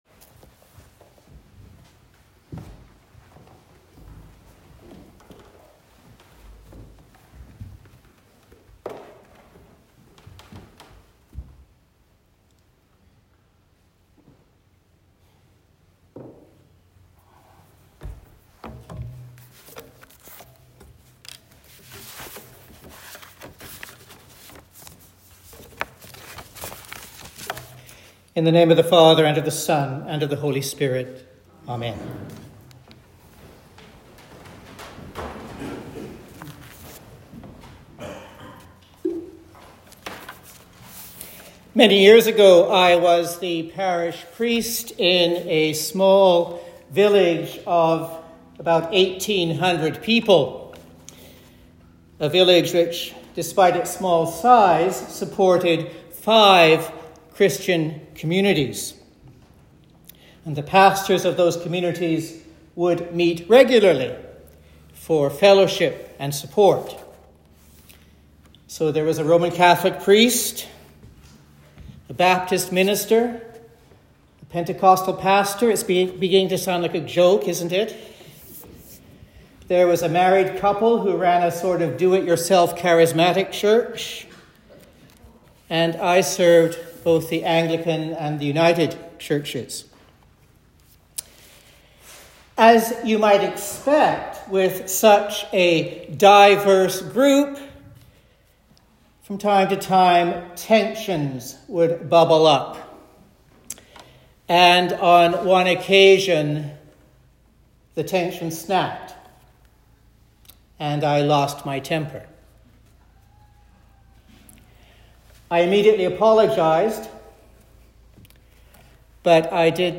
Good Friday Sermon